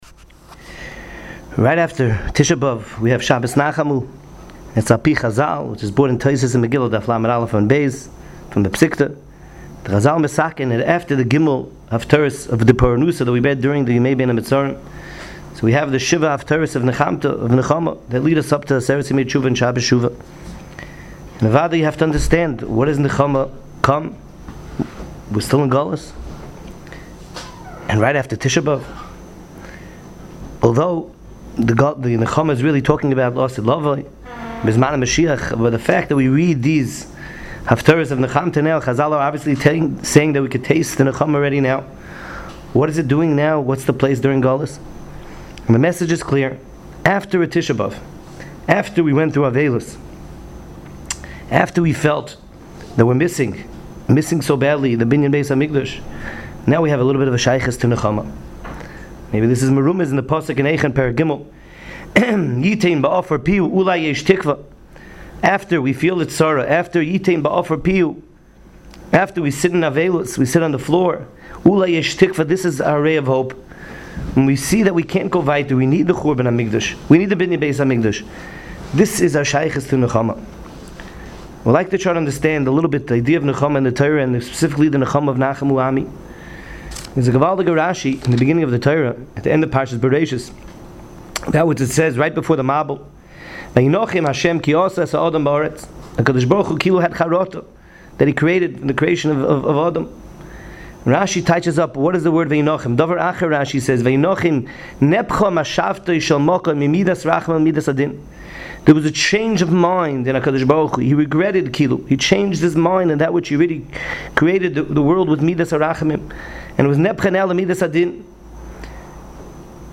Parsha Preview Audio